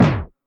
Snare (Cowboy).wav